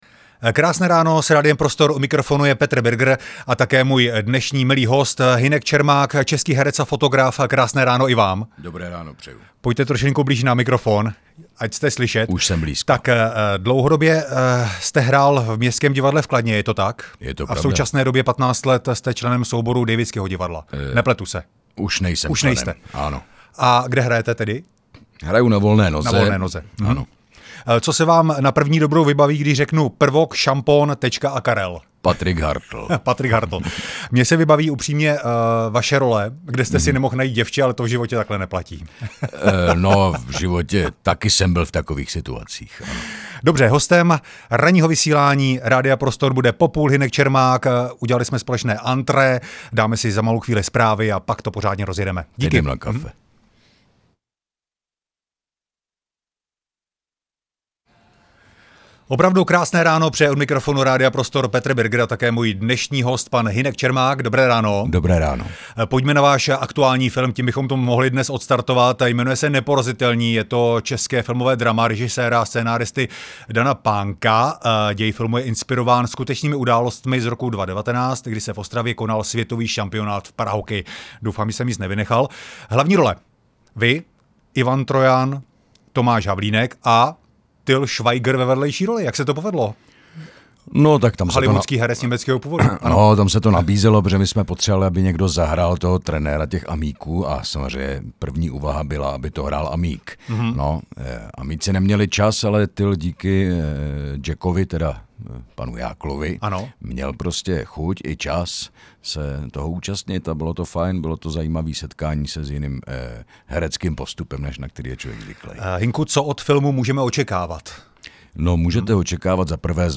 Rozhovor s hercem Hynkem Čermákem